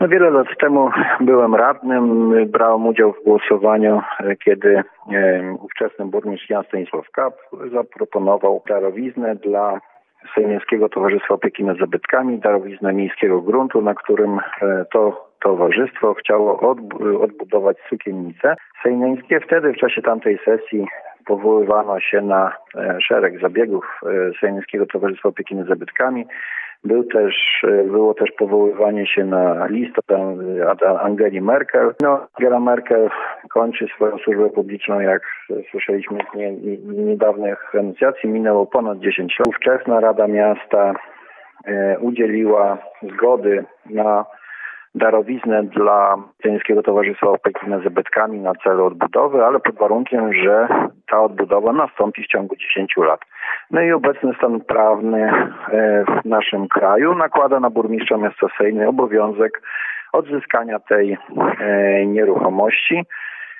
Burmistrz zaznacza, że miasto już wcześniej, kiedy upłynął 10-letni termin, zwracało się o zwrot terenu.